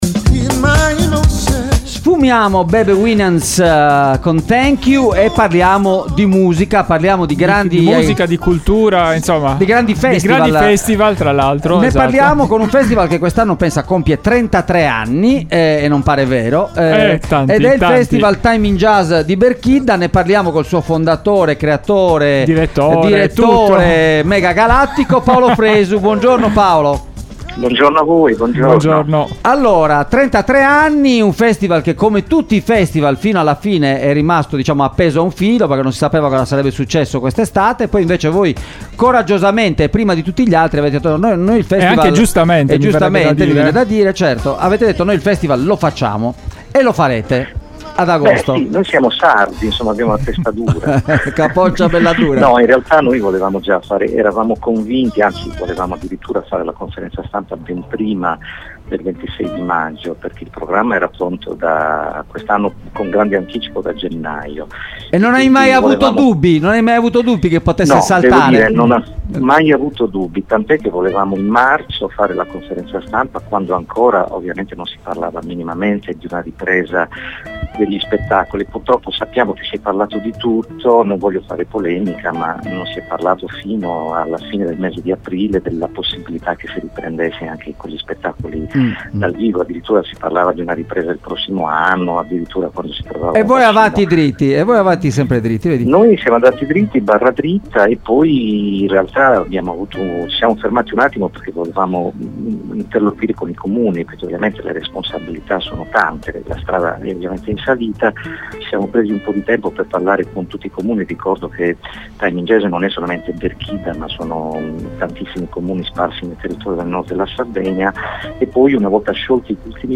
Il Time in Jazz non si ferma: ad agosto la 33esima edizione nel segno dell'anima - intervista con Paolo Fresu